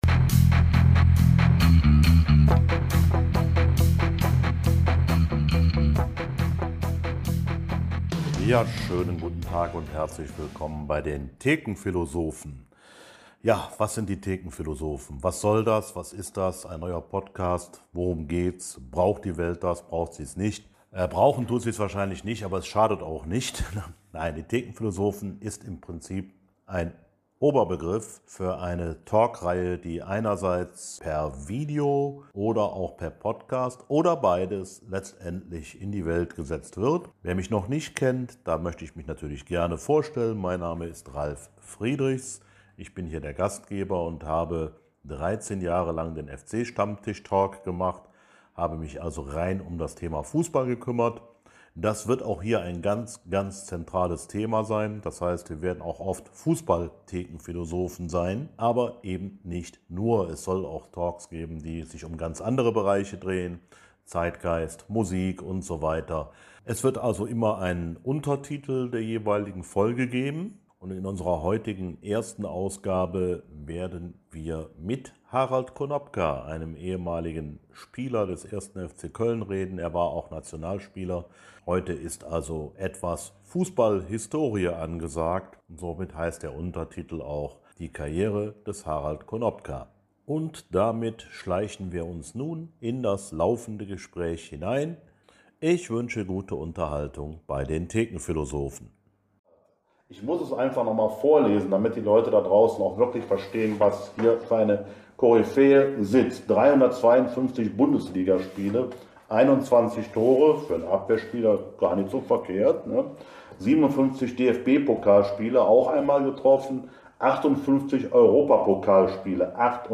Harald Konopka erzählt im Talk aus seiner großen Karriere.